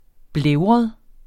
Udtale [ ˈblεwʁʌð ]